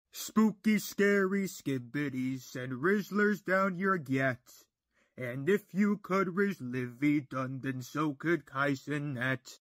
Listen and download the spooky scary skibidi meme sound effect button.